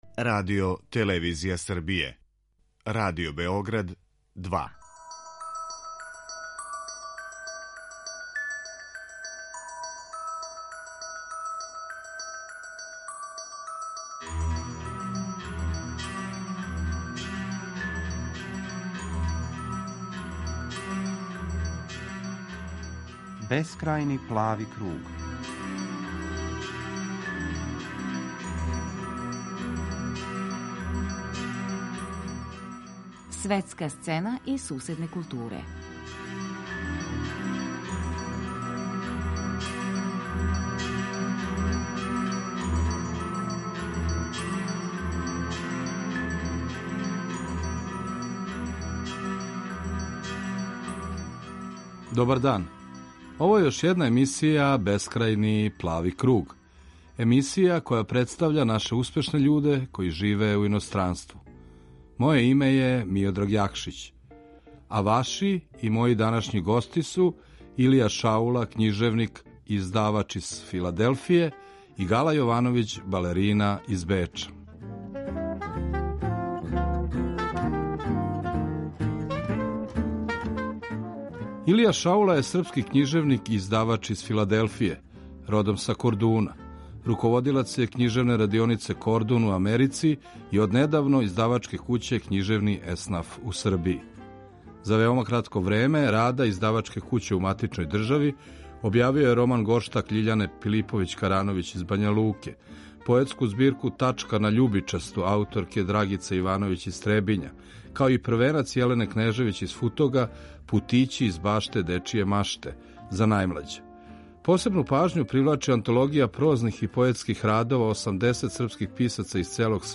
Гости